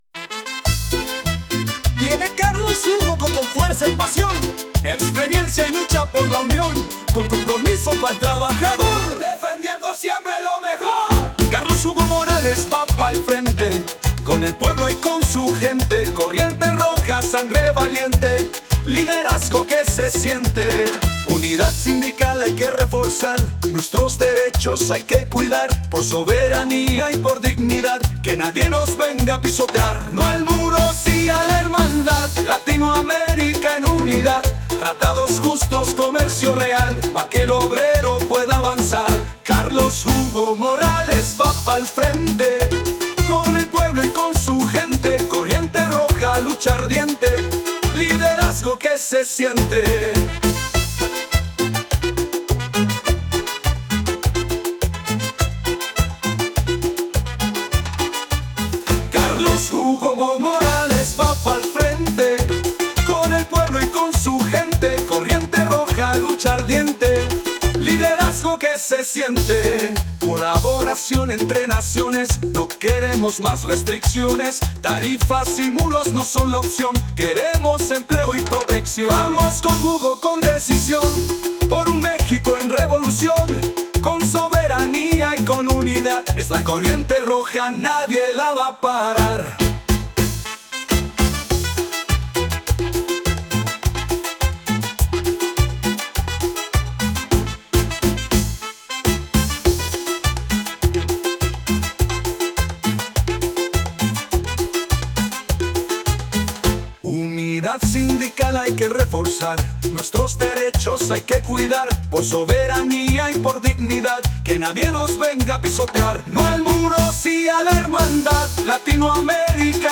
Género: Cumbia